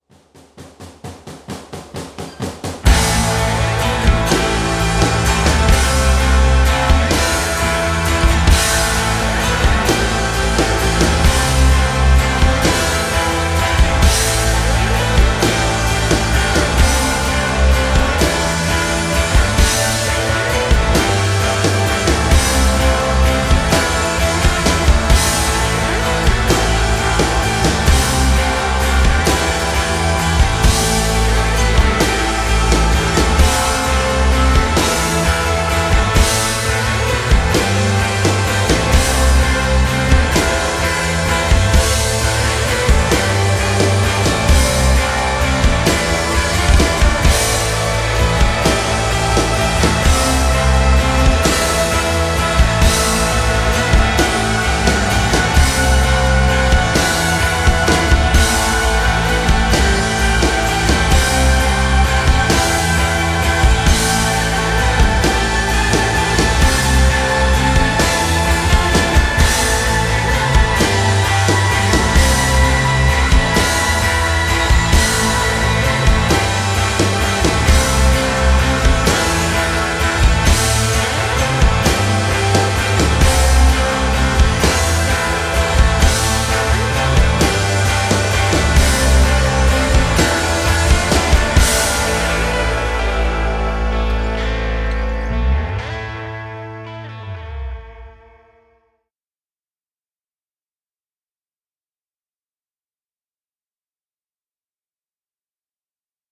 I don't think I can do too much about the harshness of the cymbals without affecting the snare sound, it's a pretty cheap set of condenser mics. We're a post rock instrumental band and I like the ambient sounds.